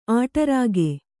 ♪ āṭarāge